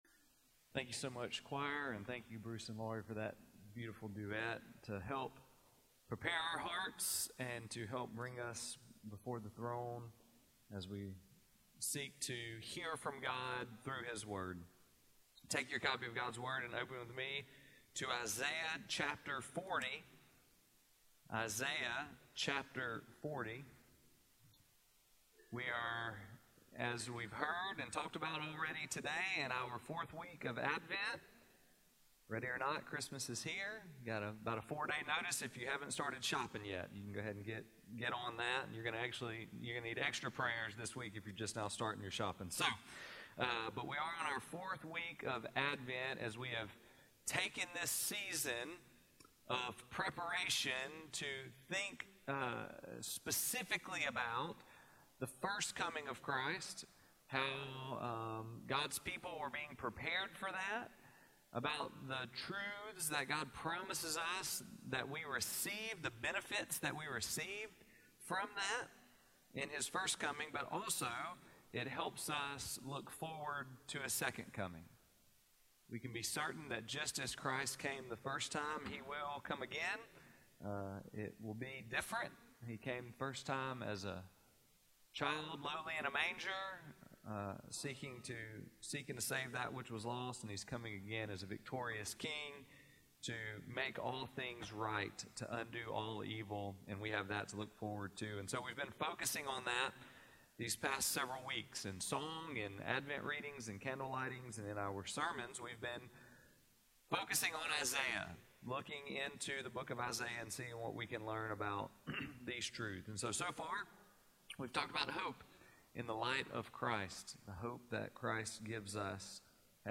Christmas 2025, The Fourth Sunday of Advent: Love